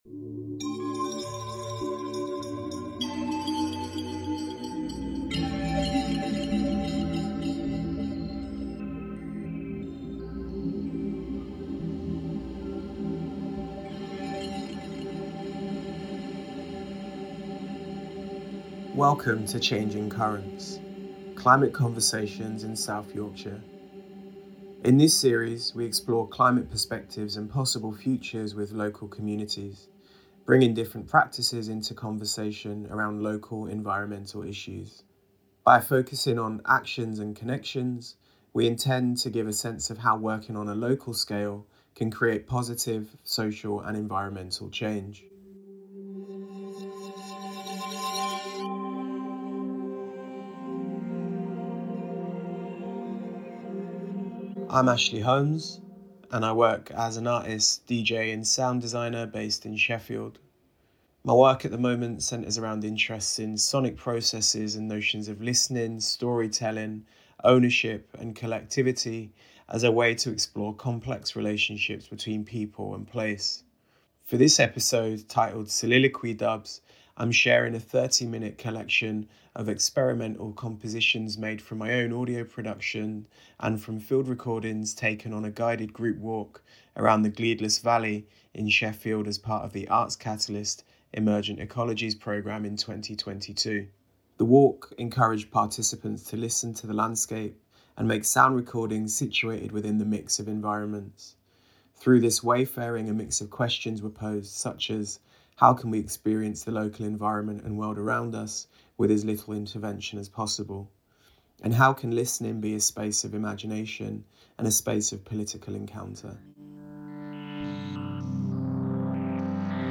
experimental compositions
field recordings taken on a guided group walk around the Gleadless Valley in Sheffield